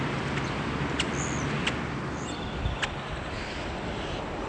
Great-tailed Grackle diurnal flight call
Bird in flight with Northern Mockingbird singing and Eastern Meadowlark calling in the background.